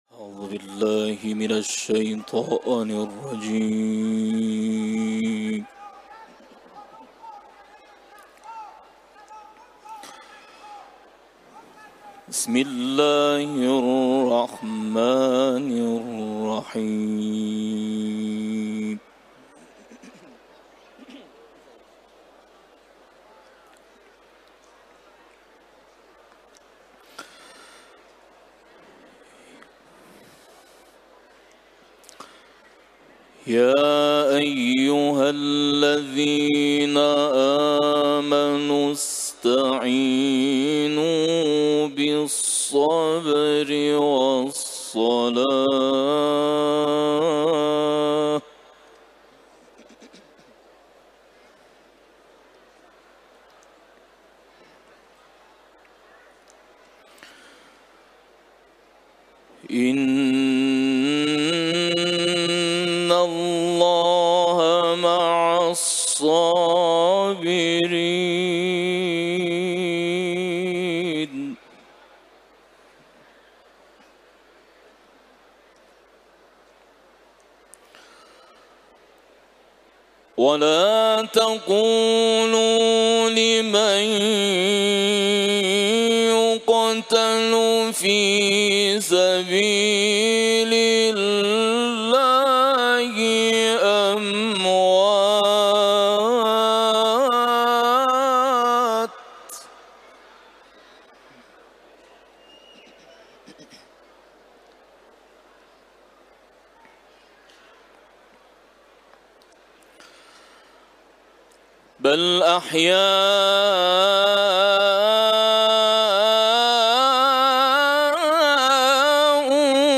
سوره بقره ، تلاوت قرآن